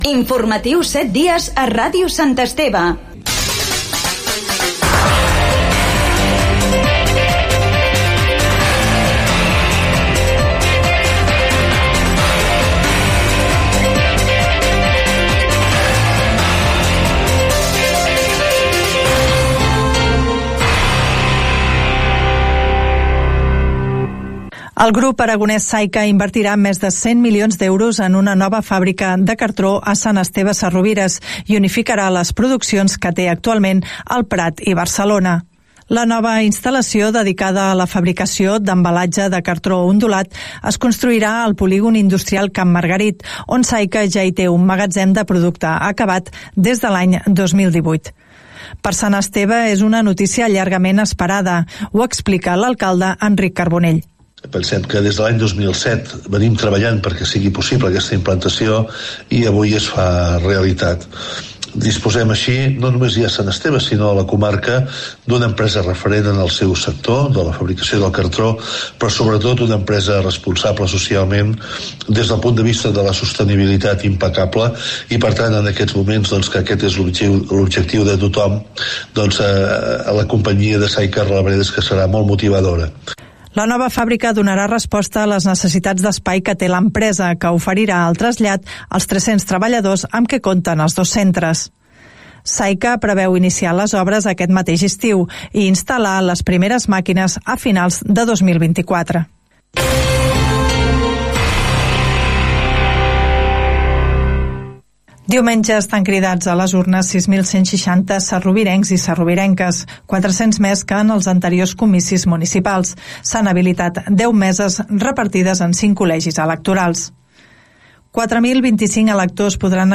Careta del programa, nova fàbrica de cartró a la localitat, amb declaracions de l'alcalde, eleccions municipals, Fundació Jaume Balmes, indicatiu del programa, curs de vendes per Internet, monitors casal d'estiu, indicatiu, etc. Careta de sortida. Gènere radiofònic Informatiu